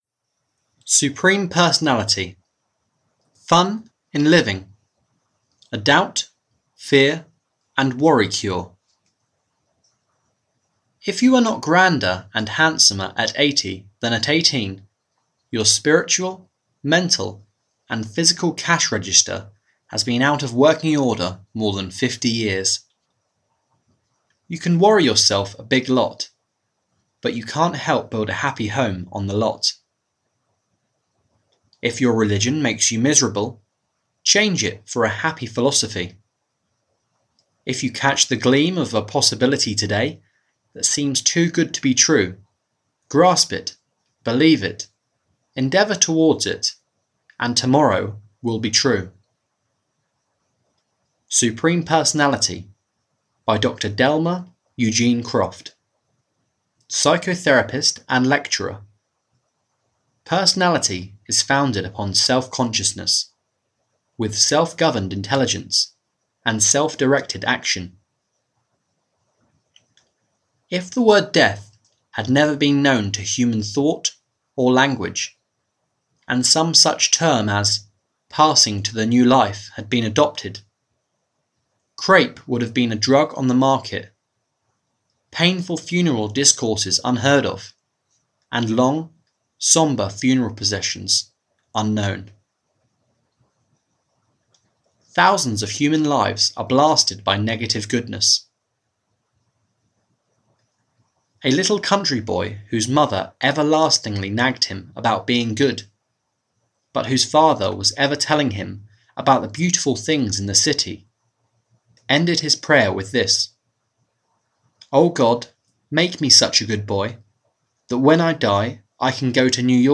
Supreme Personality (EN) audiokniha
Ukázka z knihy